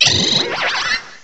cry_not_hoopa.aif